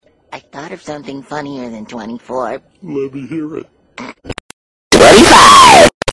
25 EARRAPE
25-earrape.mp3